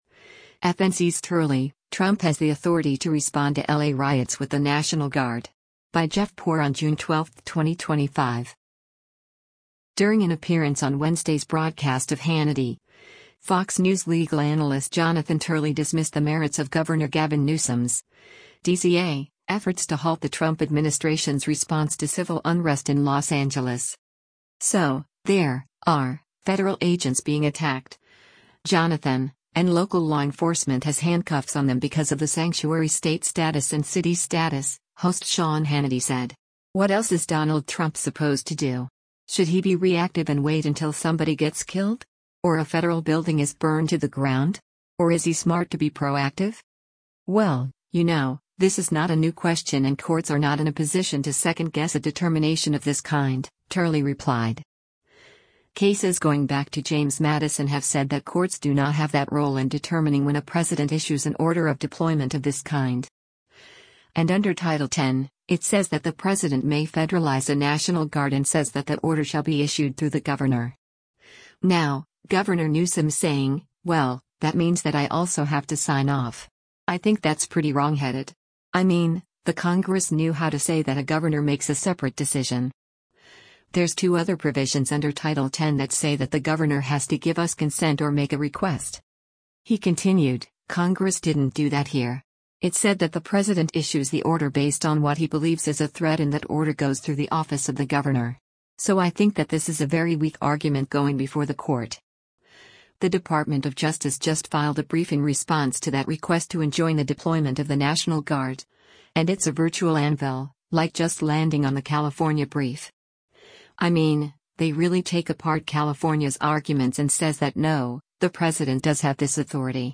During an appearance on Wednesday’s broadcast of “Hannity,” Fox News legal analyst Jonathan Turley dismissed the merits of Gov. Gavin Newsom’s (D-CA) efforts to halt the Trump administration’s response to civil unrest in Los Angeles.